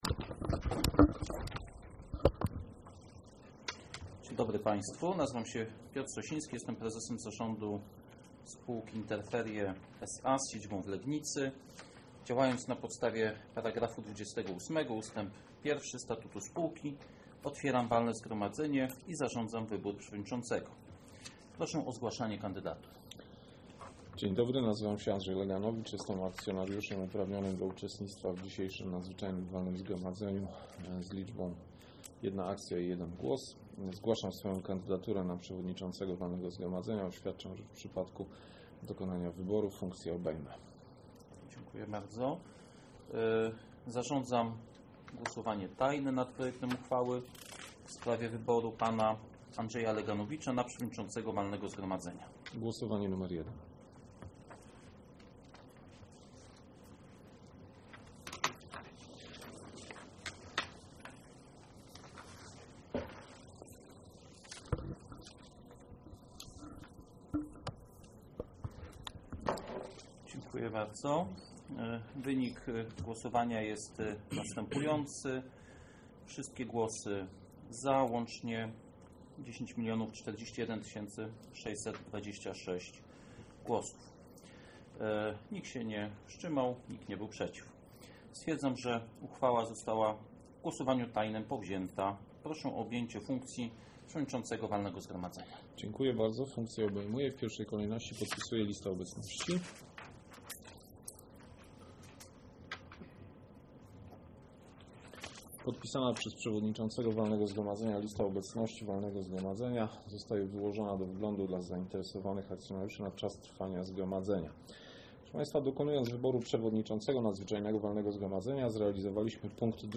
Nagranie z NWZ